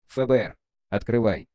На этой странице собраны звуки, связанные с работой ФБР: перехваты переговоров, сигналы спецоборудования, тревожные гудки и другие эффекты.
Звук из мема со стуком ФБР FBI Open up